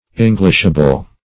Search Result for " englishable" : The Collaborative International Dictionary of English v.0.48: Englishable \Eng"lish*a*ble\, a. Capable of being translated into, or expressed in, English.